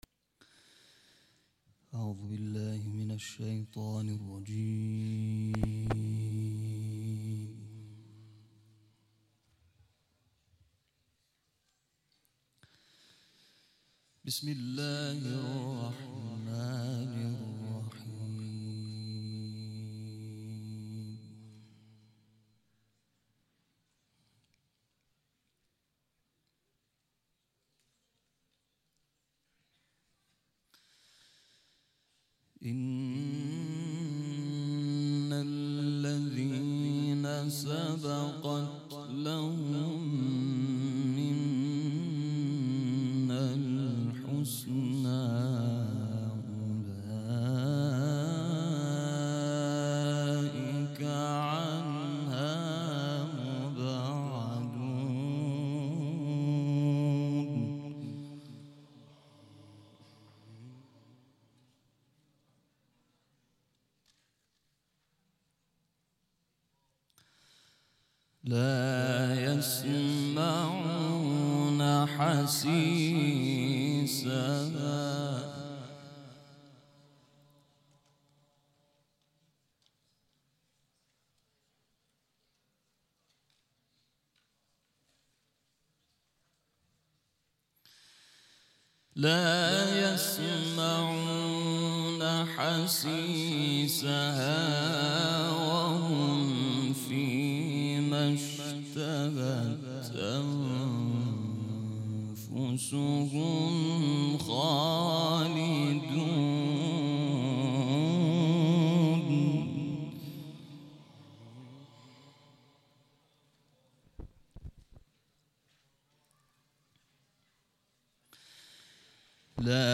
تلاوت سوره انبیاء